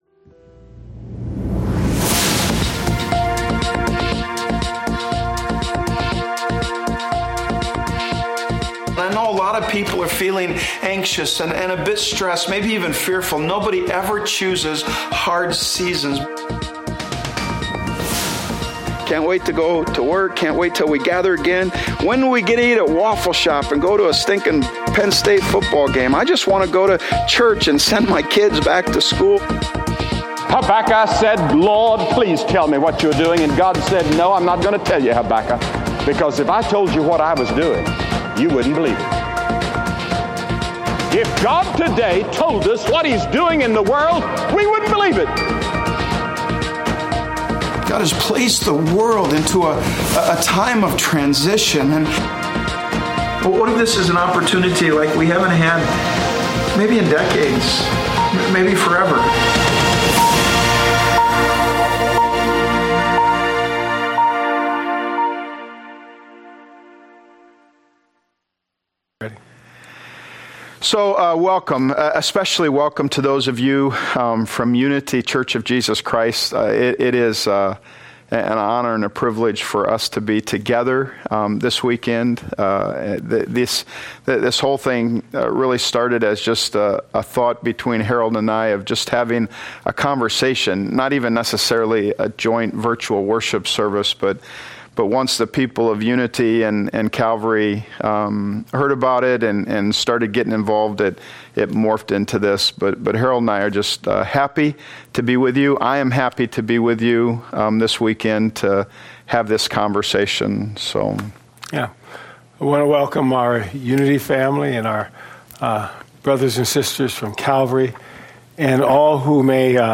A Conversation on Racial Unity | Calvary Portal | Calvary Portal